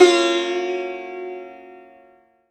SITAR LINE43.wav